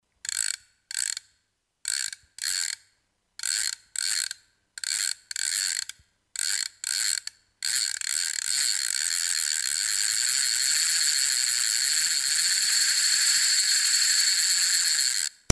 fliegenrolle3.mp3